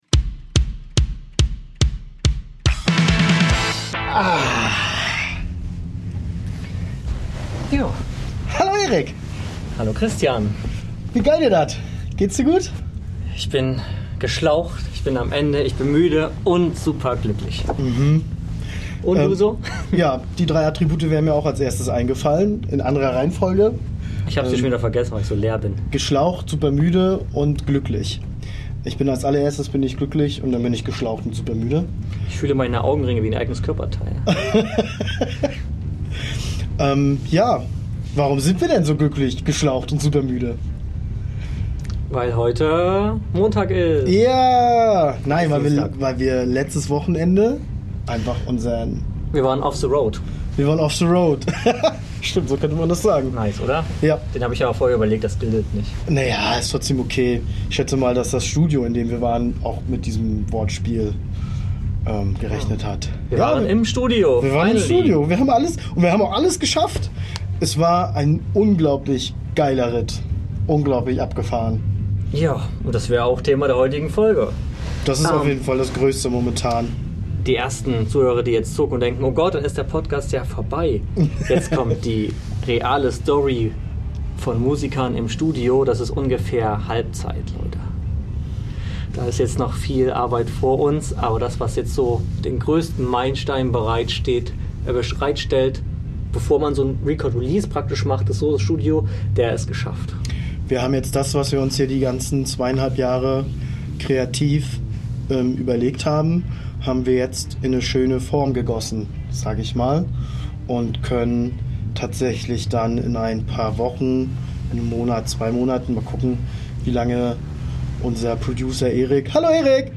Zurück aus dem Studio treffen wir uns wir diese Folge geschlaucht, müde und glücklich auf der blauen Couch wieder. Wir teilen die Erlebnisse aus dem Studio und kämpfen mit Geräuschen aus dem Nachbarraum.